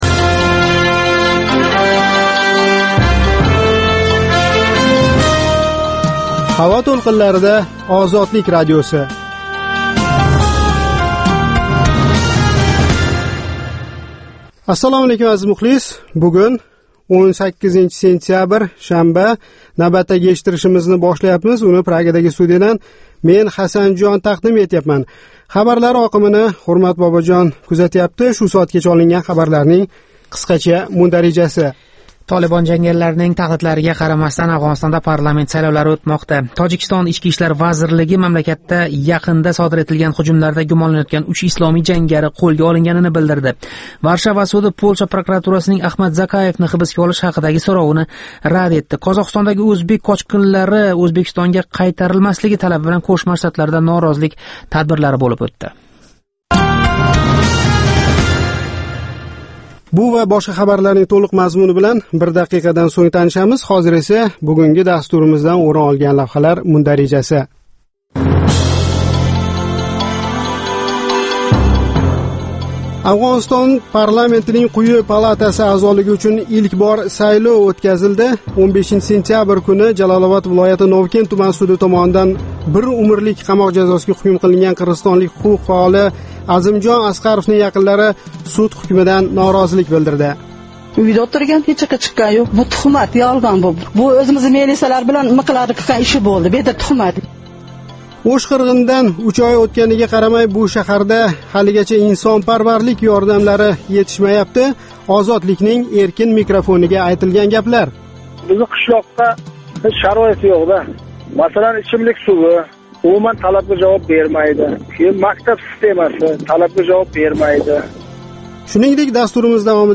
Озодлик радиосининг ўзбек тилидаги кечки жонли дастурида куннинг энг муҳим воқеаларига оид сўнгги янгиликлар¸ Ўзбекистон ва ўзбекистонликлар ҳаëтига доир лавҳалар¸ Марказий Осиë ва халқаро майдонда кечаëтган долзарб жараëнларга доир тафсилот ва таҳлиллар билан таниша оласиз